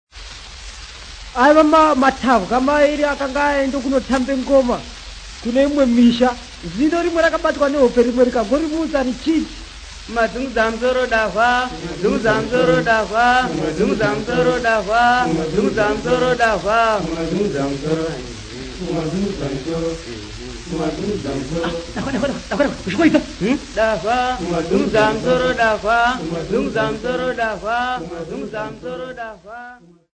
Folk Music
Field recordings
sound recording-musical
Indigenous music